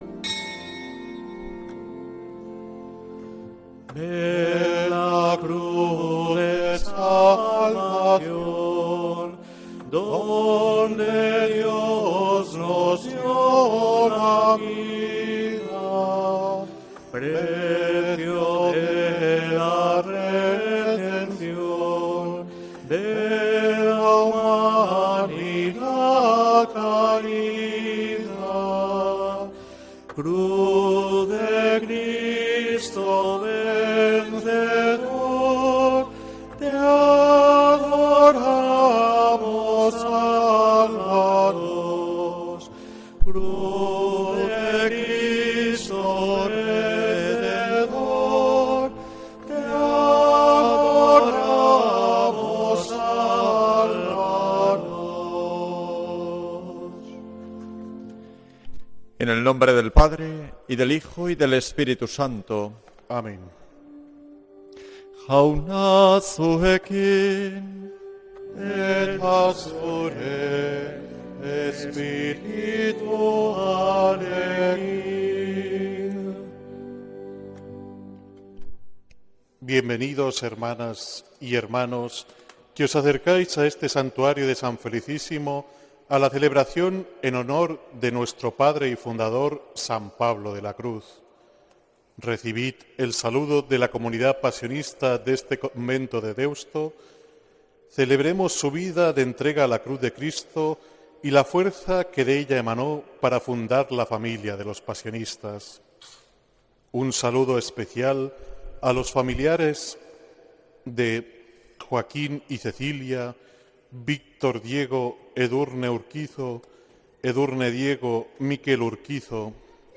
Santa Misa desde San Felicísimo en Deusto, domingo 19 de octubre de 2025